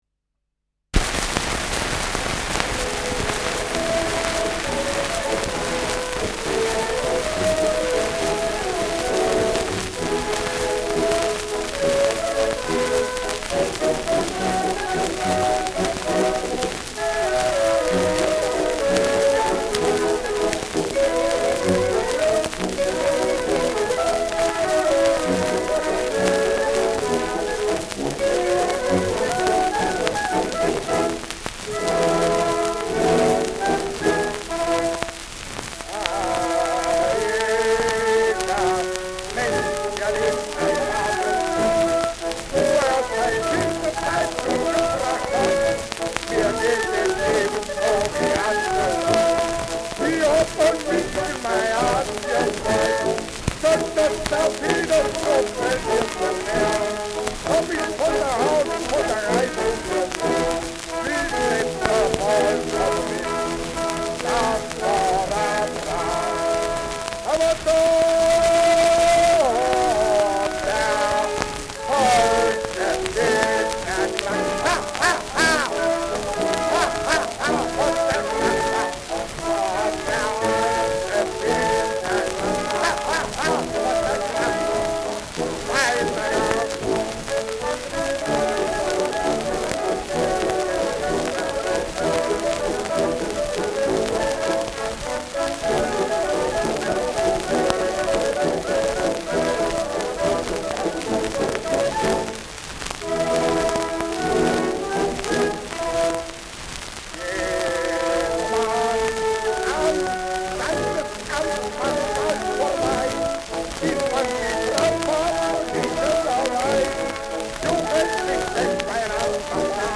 Schellackplatte
sehr starkes Rauschen
Mit Gesang.